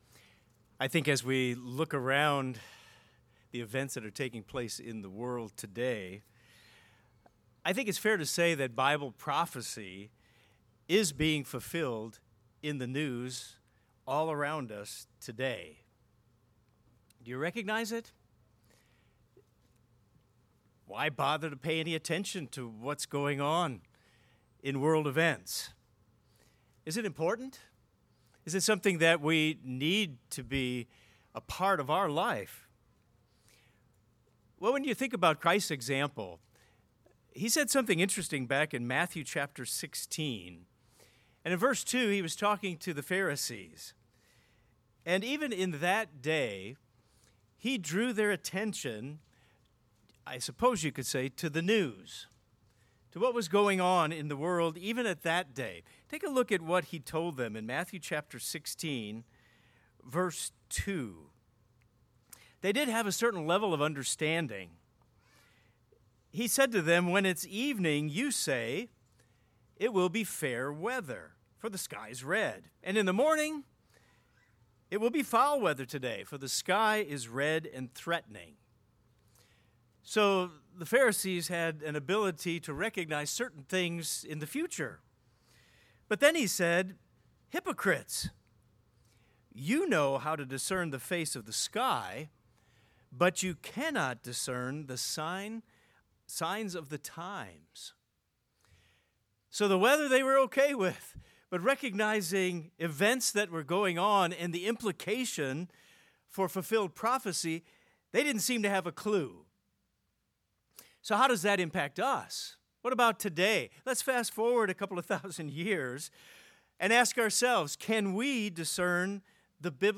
This sermon addresses where to focus our attention as we face these significant times.